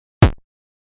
Звук удара 8 бит